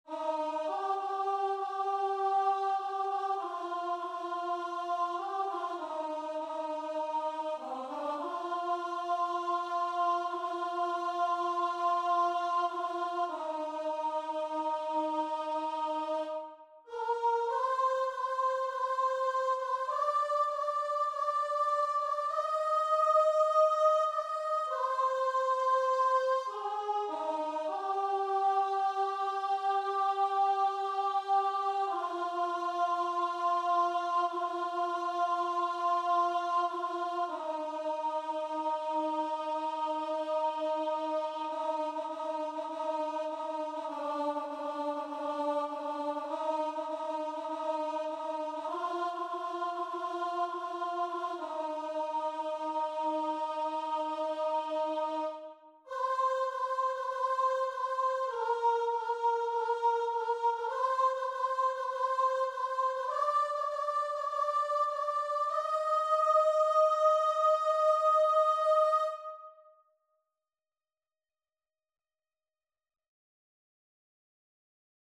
4/4 (View more 4/4 Music)
Voice  (View more Easy Voice Music)
Pop (View more Pop Voice Music)